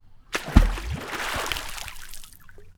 Water_15.wav